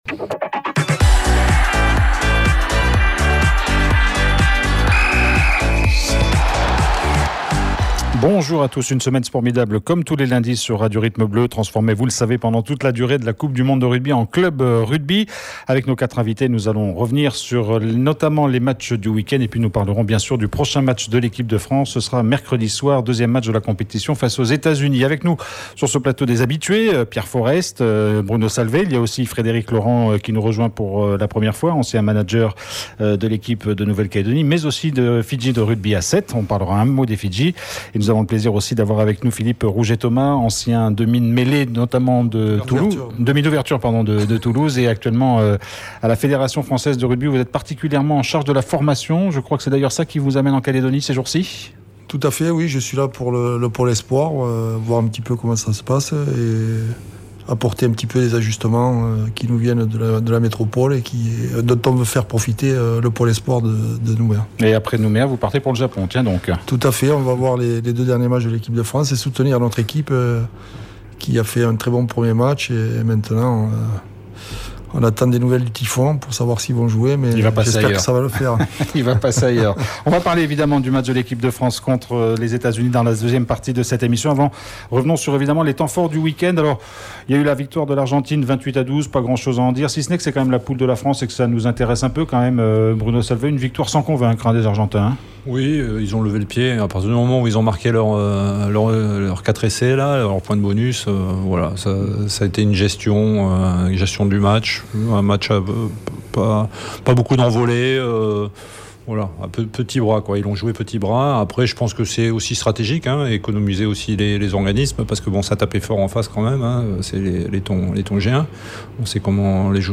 Menu La fréquence aux couleurs de la France En direct Accueil Podcasts C'EST SPORMIDABLE : 30/09/19 C'EST SPORMIDABLE : 30/09/19 30 septembre 2019 à 08:34 Écouter Télécharger Un magazine spécial Coupe du Monde de rugby.